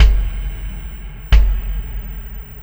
Kick Particle 01.wav